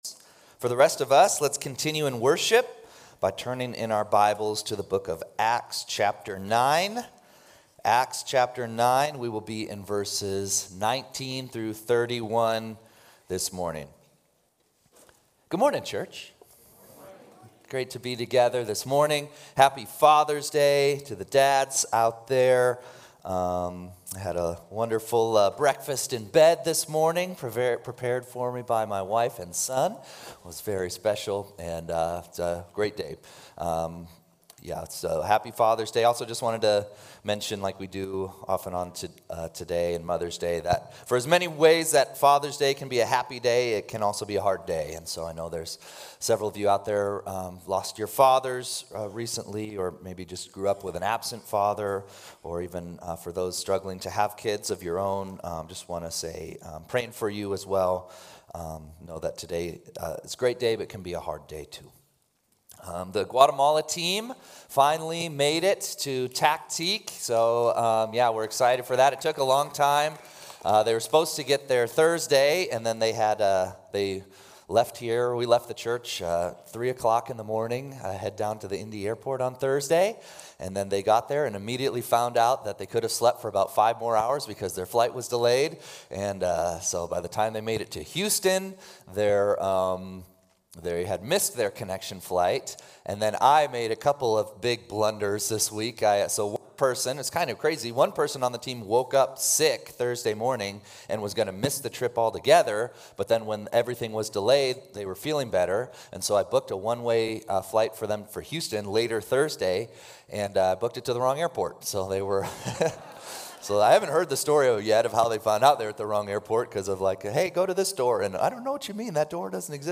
6-15-25-Sunday-Service.mp3